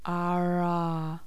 Pulmonische Konsonantensymbole
Es ertönt der Konsonant in [a_a]-Umgebung.